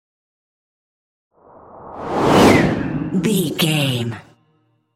Whoosh airy
Sound Effects
futuristic
whoosh
sci fi